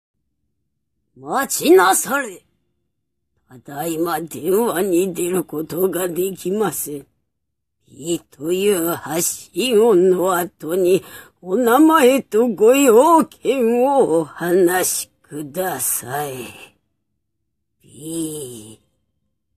大ババ様が留守番電話サービスの受け応えをした / 風の谷のナウシカより、大ババ様